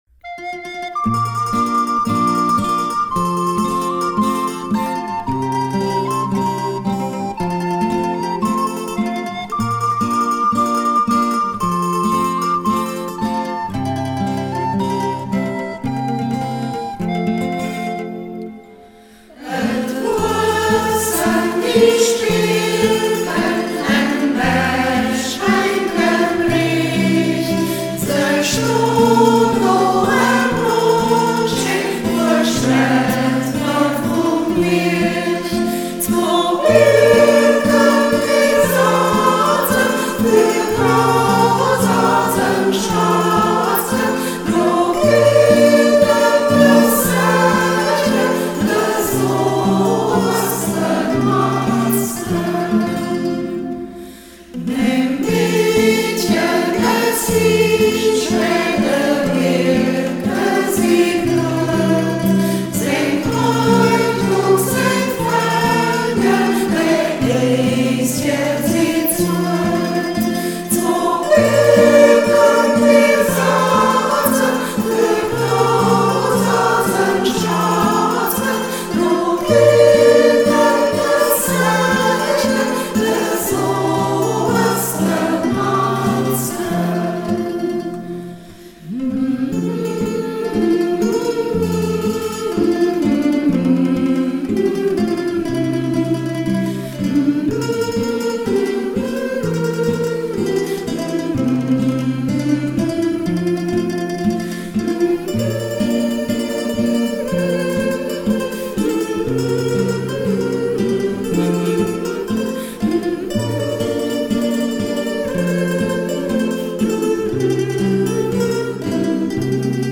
Maria Haydl (Text), Josef Beer (Melodie)
Sälwerfäddem Hermannstadt • Umgangssächsisch • 2:44 Minuten • Herunterladen
Ortsmundart: Frauendorf